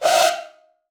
SOUTHSIDE_percussion_pull_up.wav